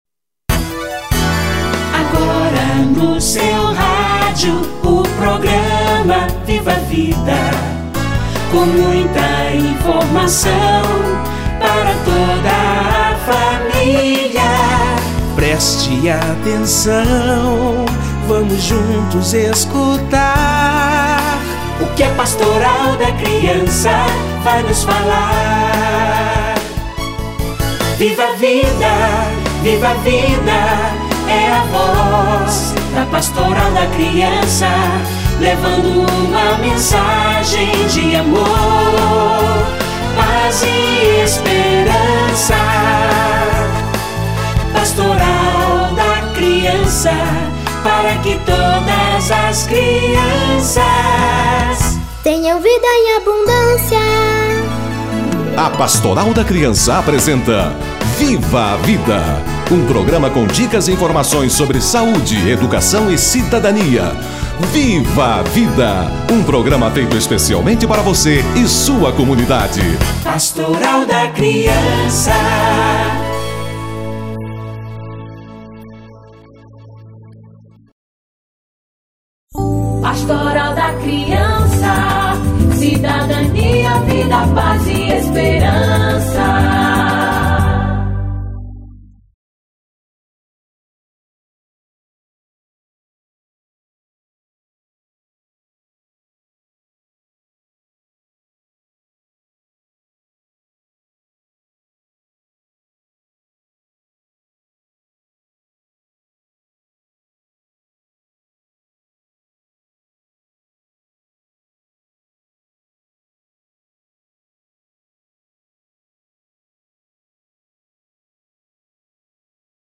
Alimentação na infância - Entrevista